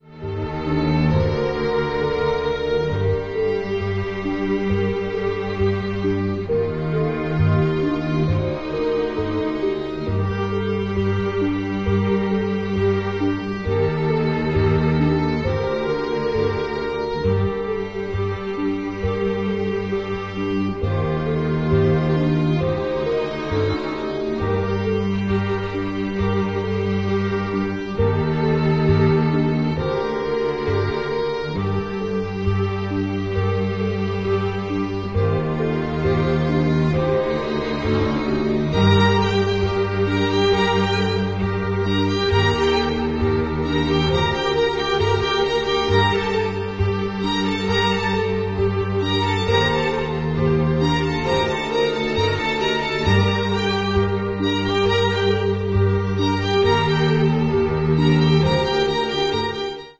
the soundtrack album from the 2000 film